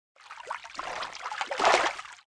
fishing_catch.wav